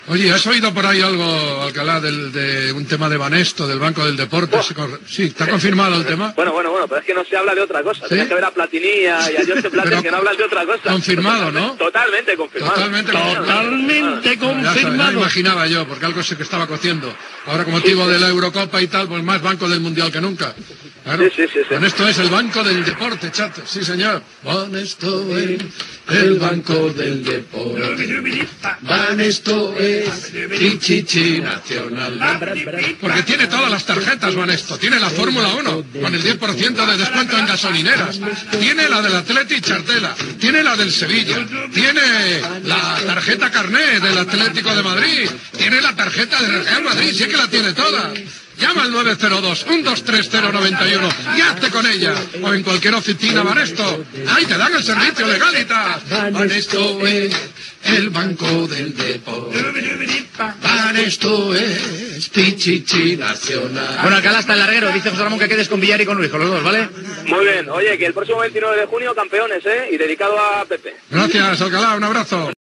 Publicitat del Banco Banesto amb esment a l'Eurocopa de Futbol masculí
Esportiu